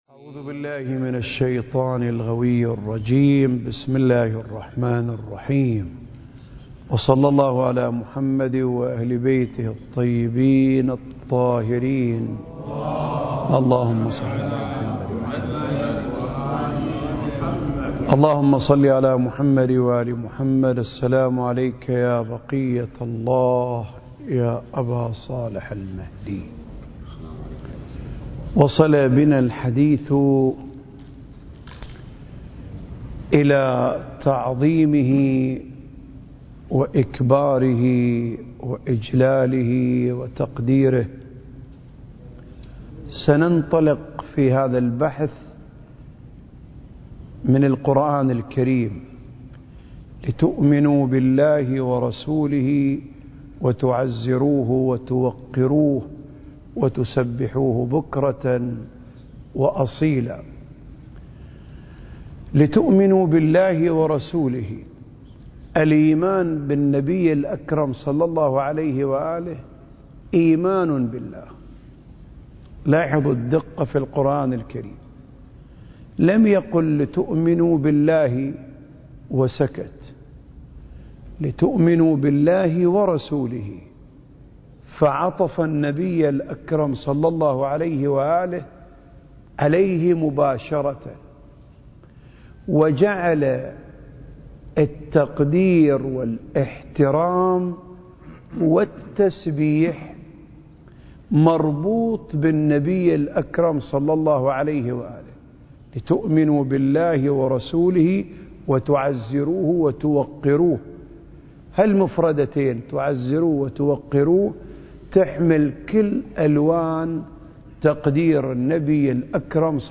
سلسلة محاضرات: آفاق المعرفة المهدوية (5) المكان: الأوقاف الجعفرية بالشارقة التاريخ: 2023